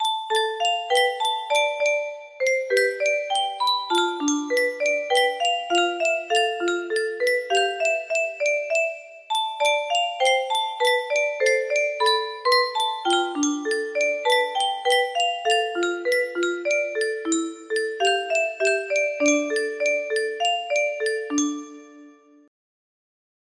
force music box melody